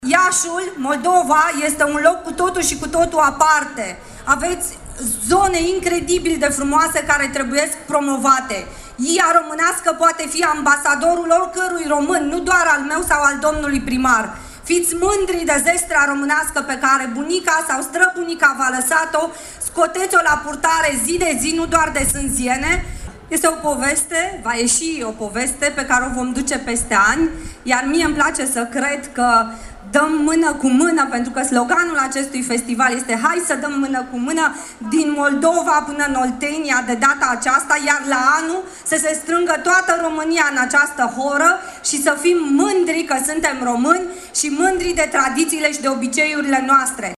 Piața Palatului și pietonalul Ștefan cel Mare din Iași găzduiesc, astăzi, Festivalul RomânIA autentică.